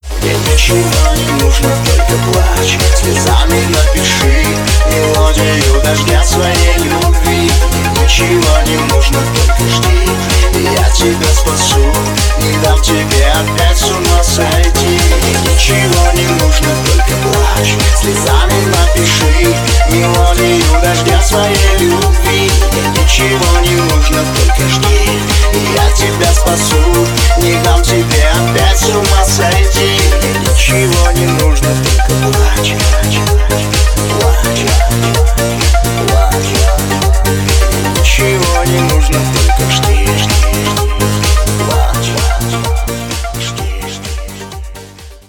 • Качество: 320, Stereo
мужской вокал
remix
dance
club